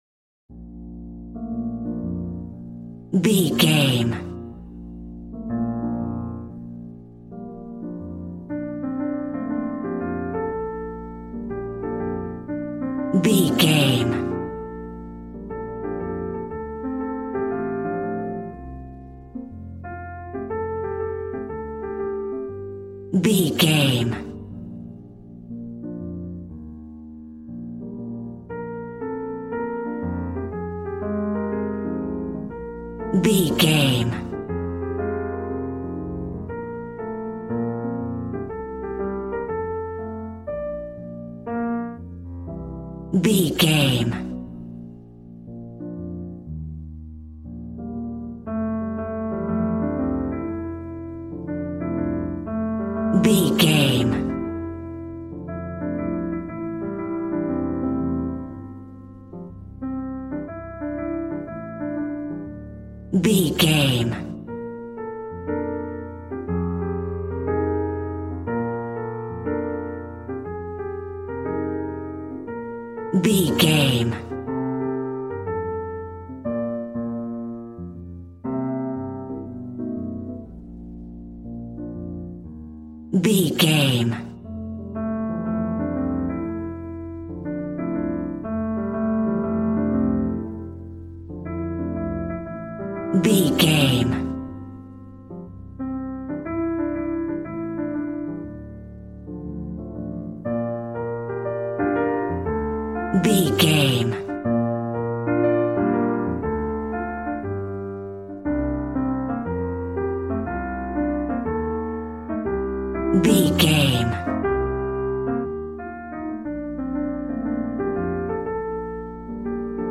Smooth jazz piano mixed with jazz bass and cool jazz drums.,
Aeolian/Minor